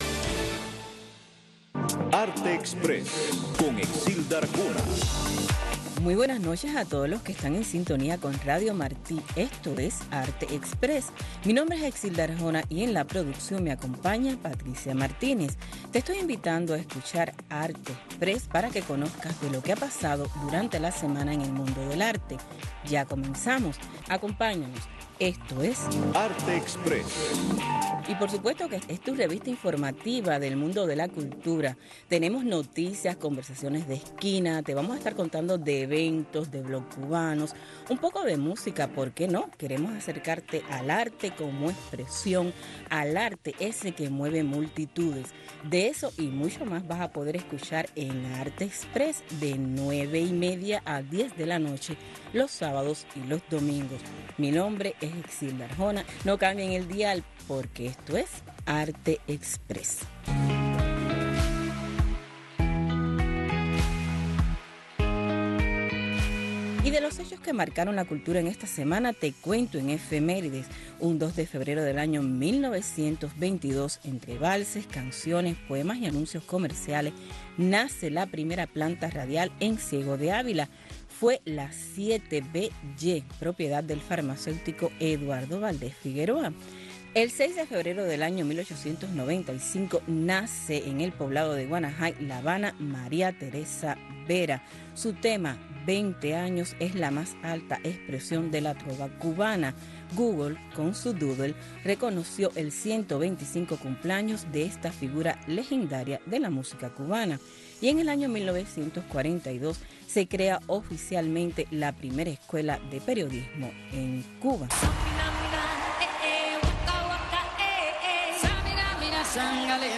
La revista “ARTExpress” conversó con un dúo de artistas cubanos que siendo pareja en la vida decidieron unir arte y amor para ofrecer una muestra con un acabado visual y un toque único. La muestra, impregnada del halo misterioso que rodea el universo femenino, consiste en una veintena de obras...